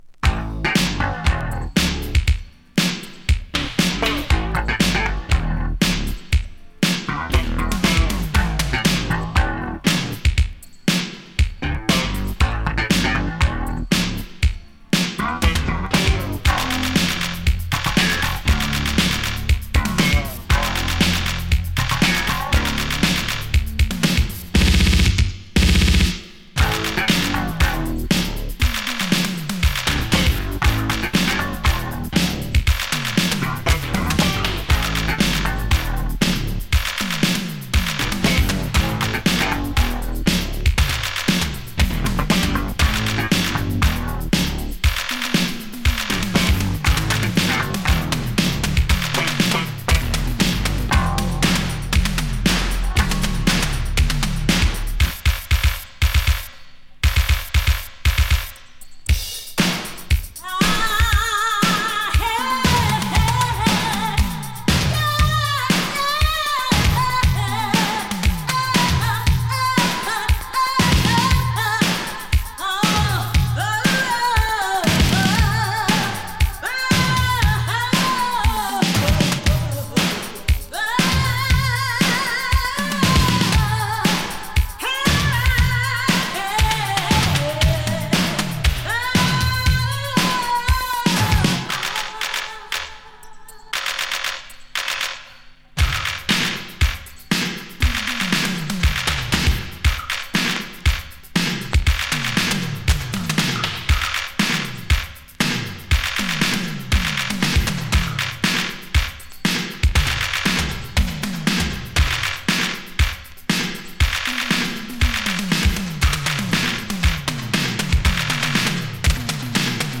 German Electro Boogie!
ヴォコーダーをフィーチャーした哀愁系ニューウェイヴ・ディスコ～エレクトロ・ブギー！
【NEW WAVE】【BOOGIE】